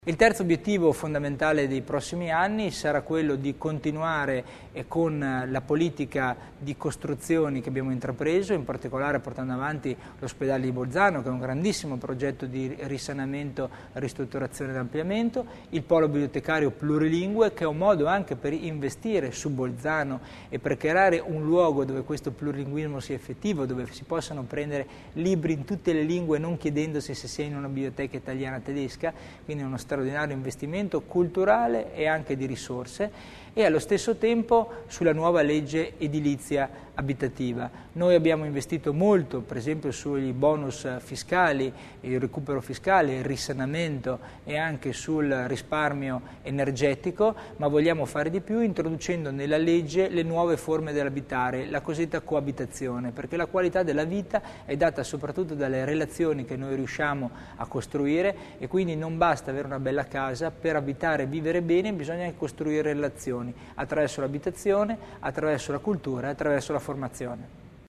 Si è svolta questa mattina presso lo spazio espositivo “Archimod” la conferenza stampa di metà legislatura del vicepresidente della Provincia, C. Tommasini.